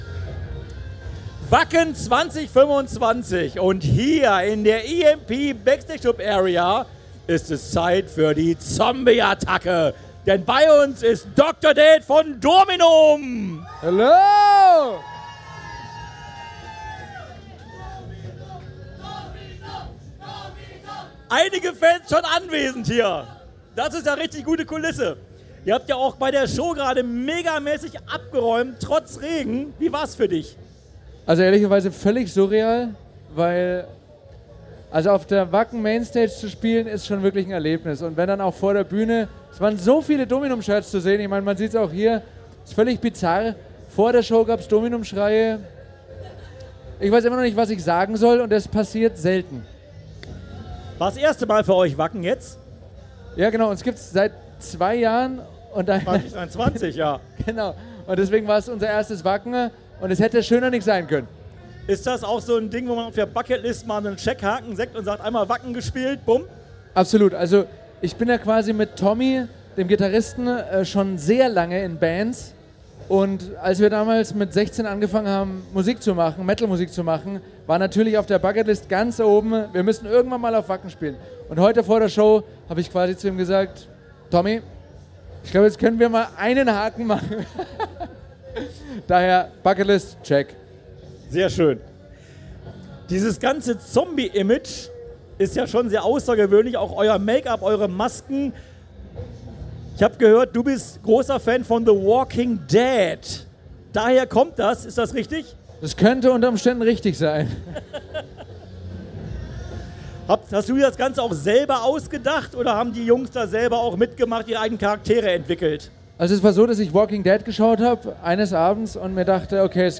Wacken 2025 Special - Dominum - Live aus der EMP Backstage Club Area